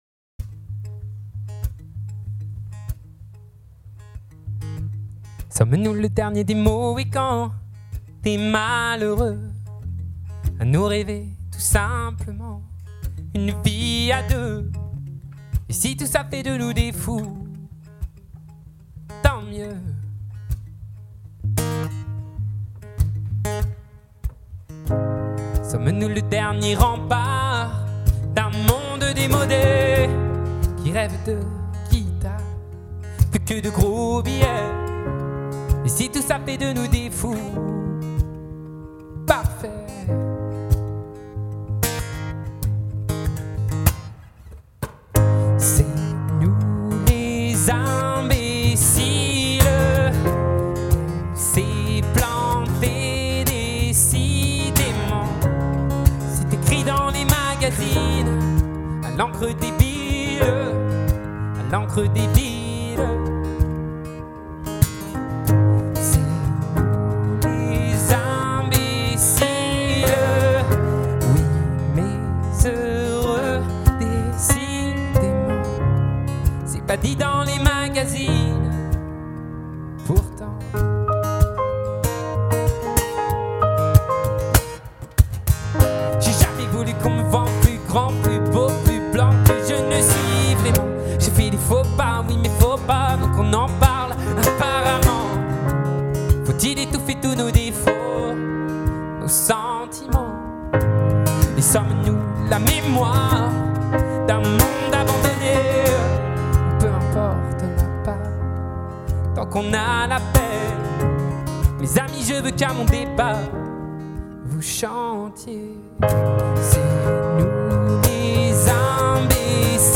Private Concert